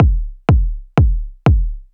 I used a kick that I’ve made myself. It have been eq’ed and compressed when I made it, but had to eq it a bit more after the overdrive to get rid of too much low end and mids that got accentuated.
The first one is the original without the Hot Tubes.